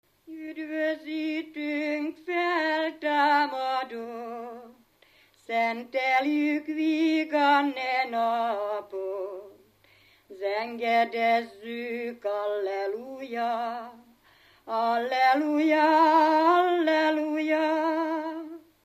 Felföld - Bars vm. - Barslédec
ének
Stílus: 2. Ereszkedő dúr dallamok